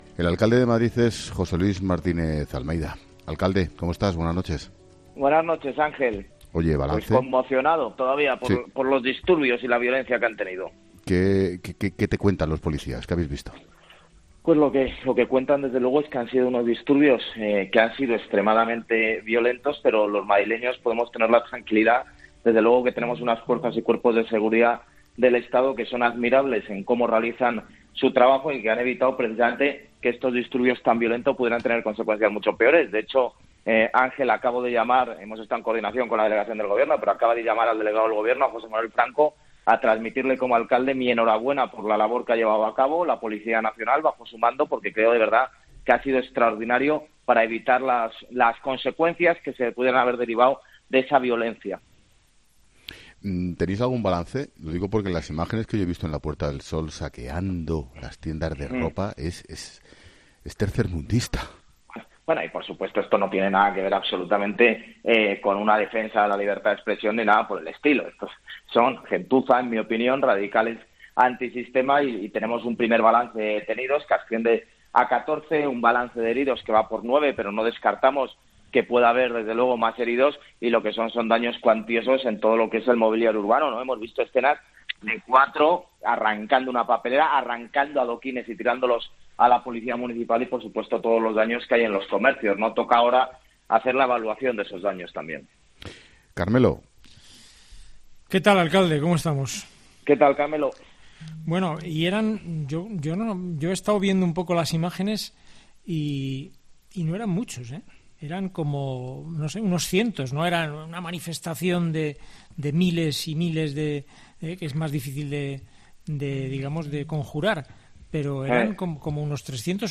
El alcalde de Madrid ha entrado en 'La linterna' para hacer el primer balance de heridos tras los disturbios que se han dado a última hora del día en el centro de Madrid